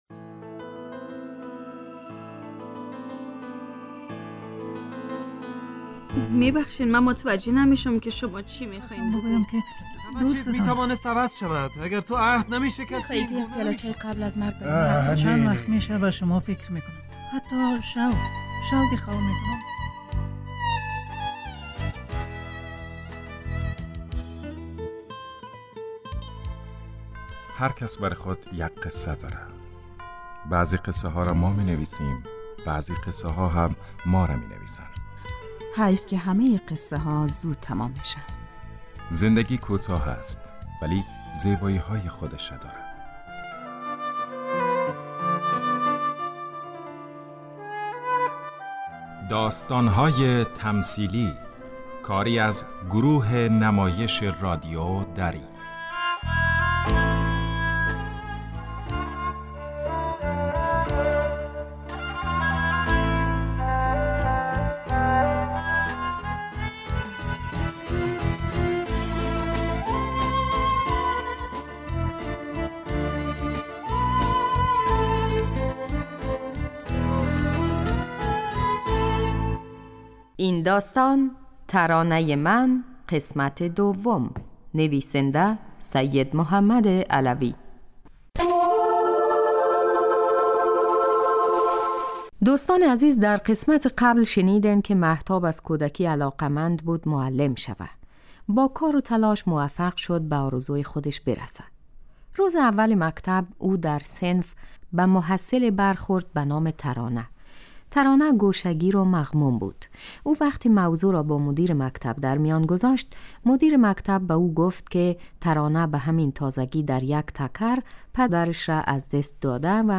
داستان تمثیلی / ترانه من